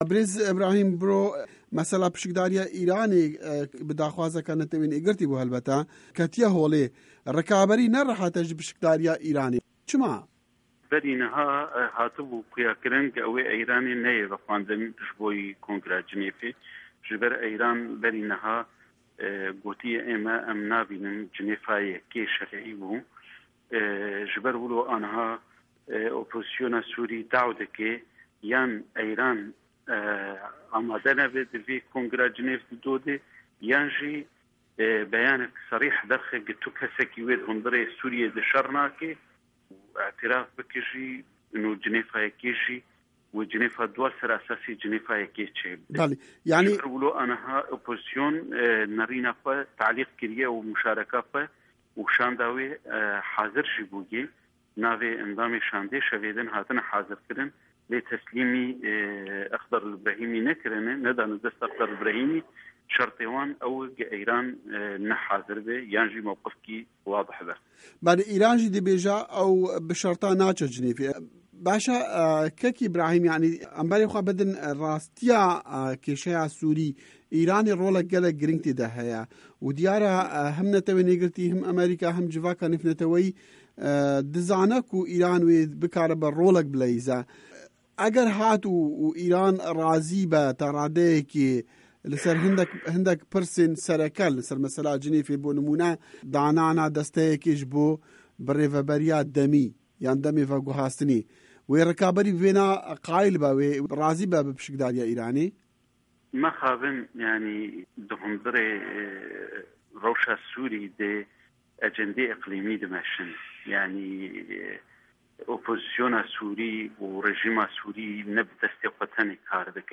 Hevpeyivin